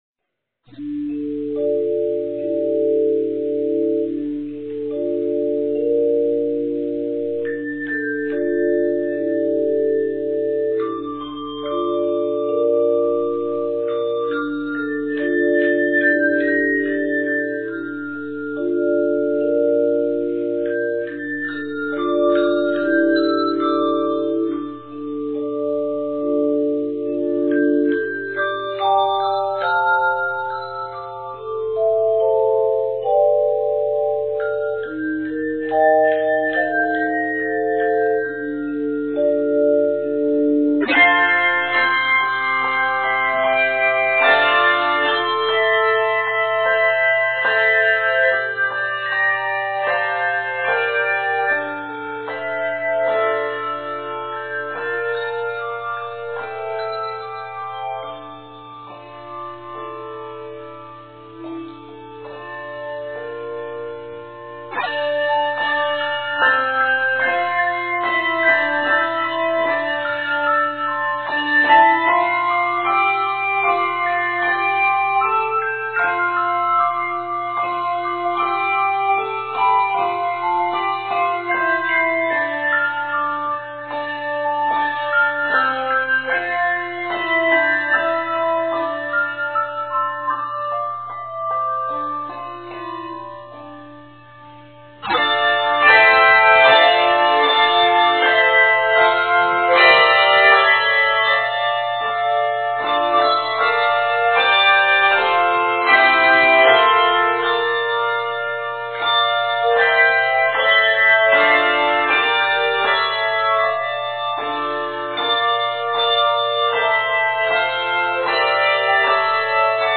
Octaves: 3